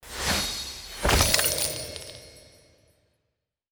chest_normal.wav